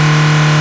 MOTOR7.mp3